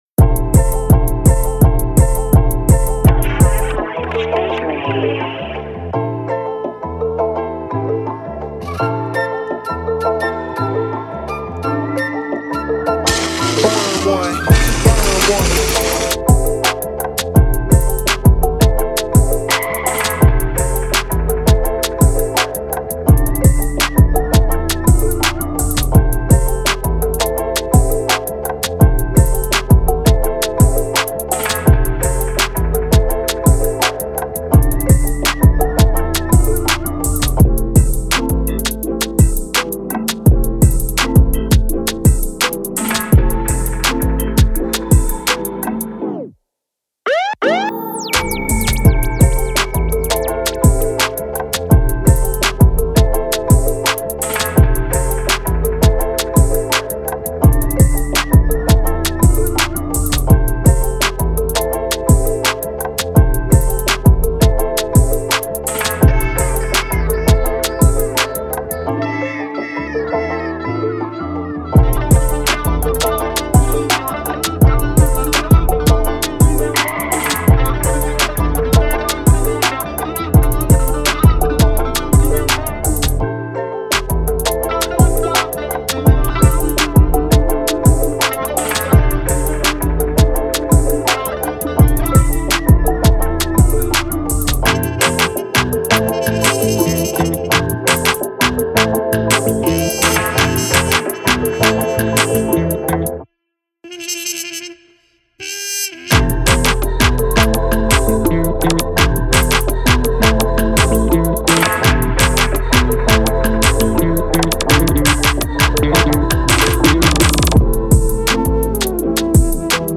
Here's the official instrumental